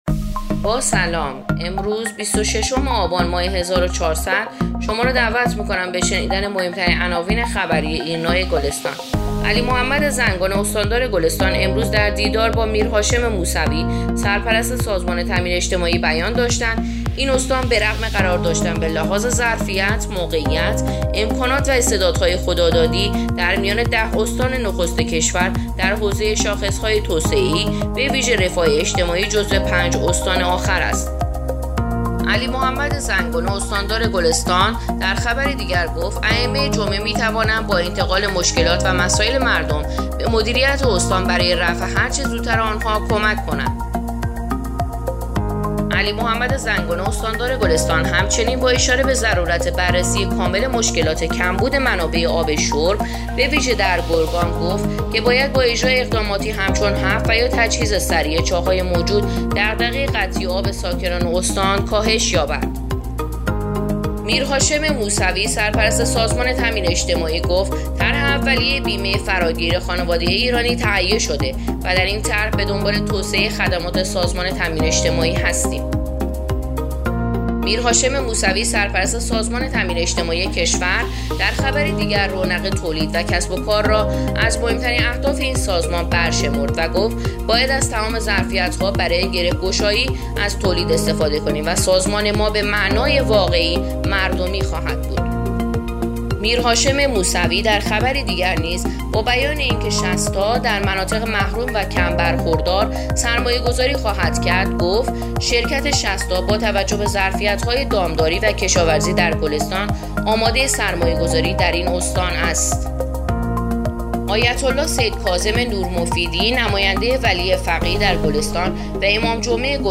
پادکست: اخبار شبانگاهی بیست و ششم آبان ایرنا گلستان